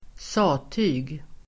Uttal: [²s'a:ty:g]